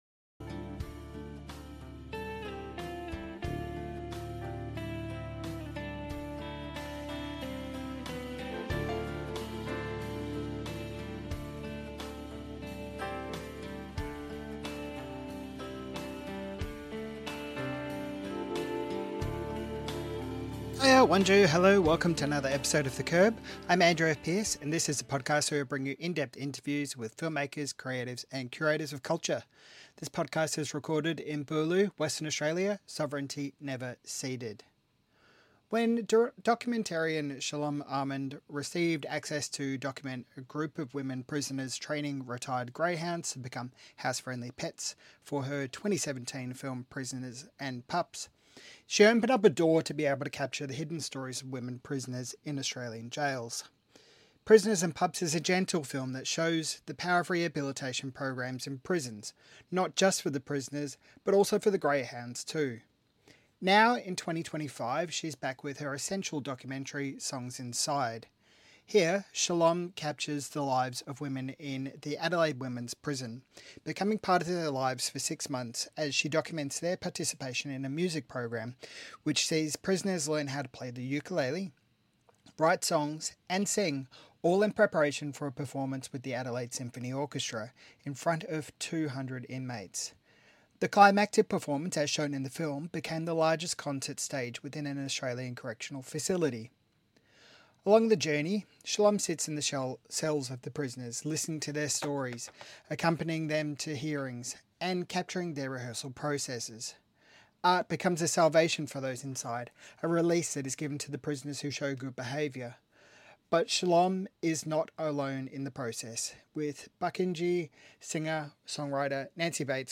MIFF Interview